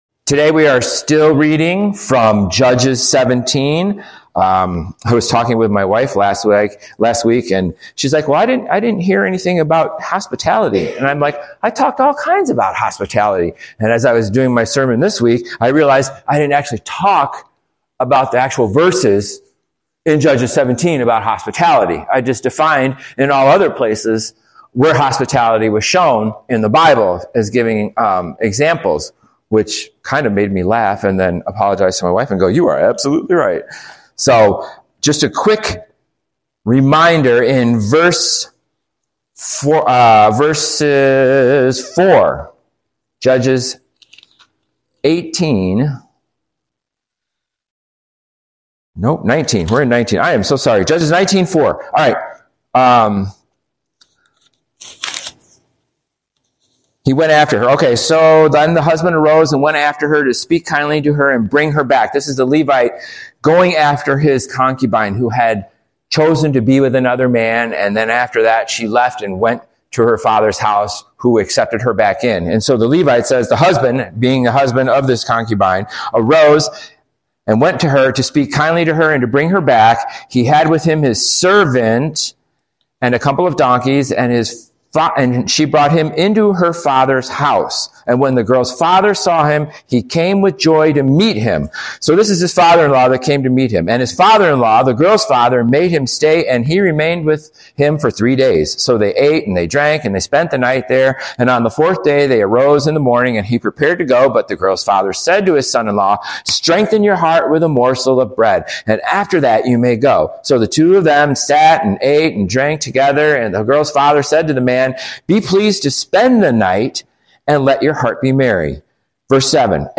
Easily listen to Grace Fellowship Church Ashton - Weekly Messages